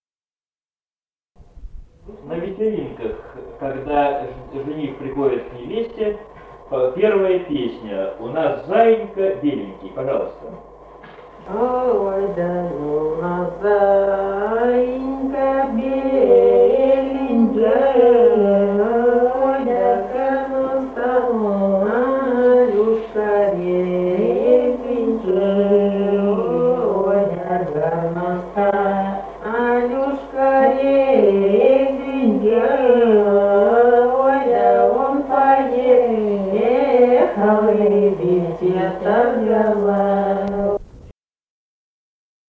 Республика Казахстан, Восточно-Казахстанская обл., Катон-Карагайский р-н, с. Белое, июль 1978.